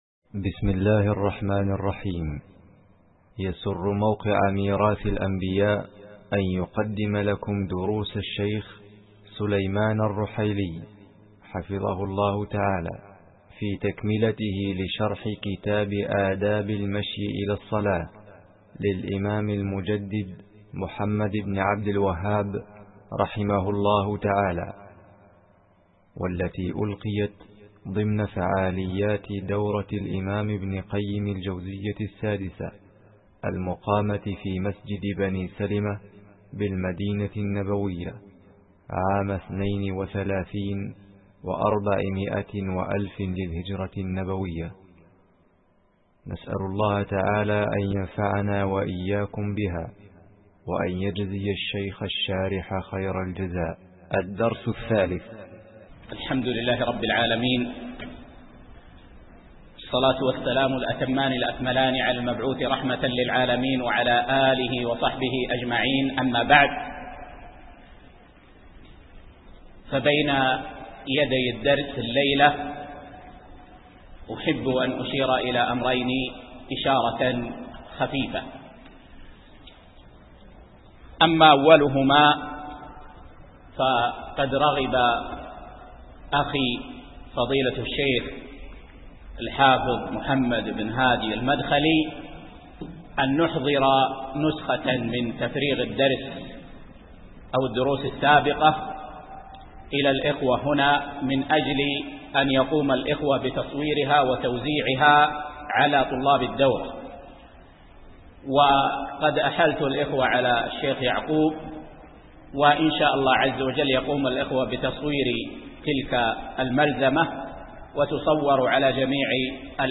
الدرس العاشر